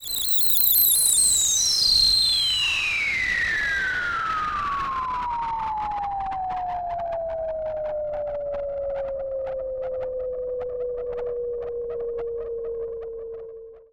Roland E Noise 08.wav